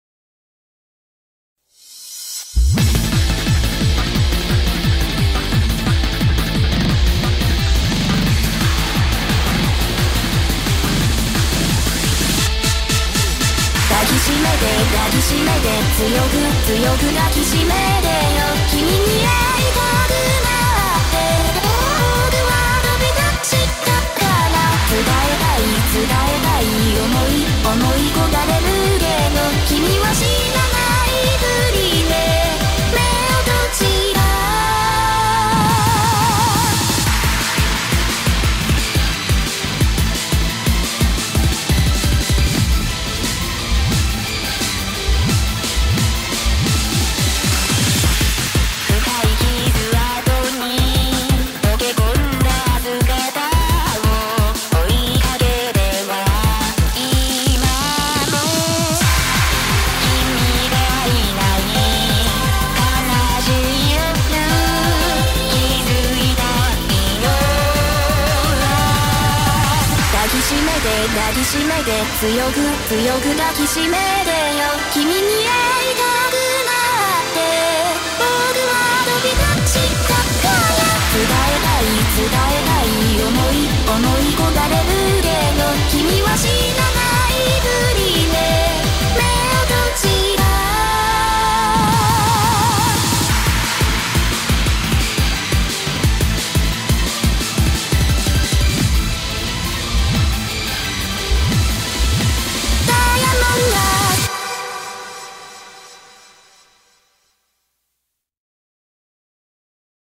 BPM175
Audio QualityPerfect (Low Quality)
sorry for the low audio :c